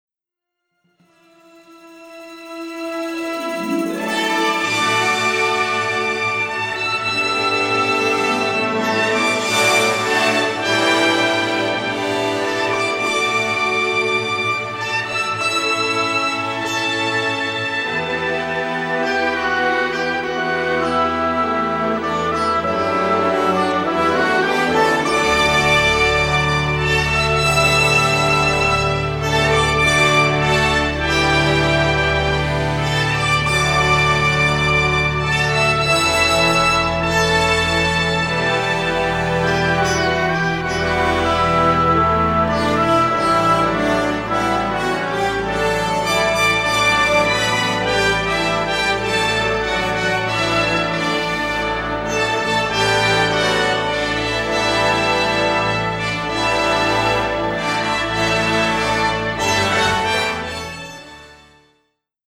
full of heartfelt themes, lilting waltzes, Indian flourishes